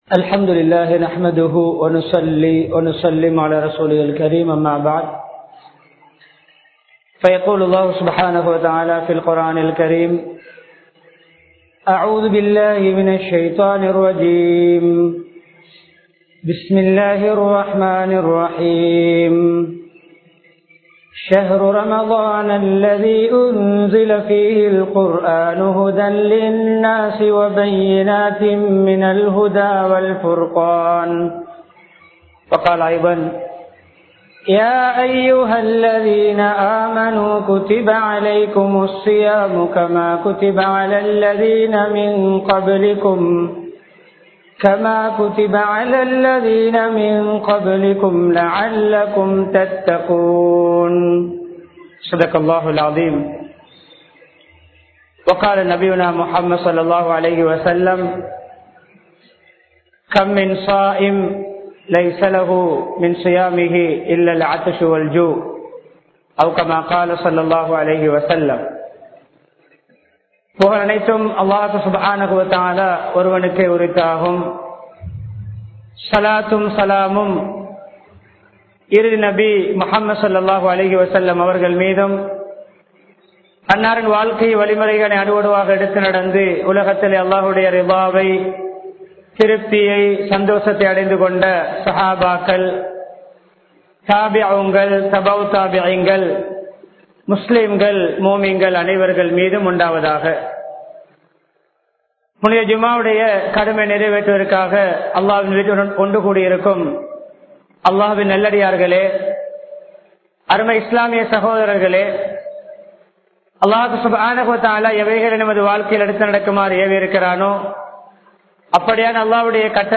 ரமழானில் நமது கடமைகள் | Audio Bayans | All Ceylon Muslim Youth Community | Addalaichenai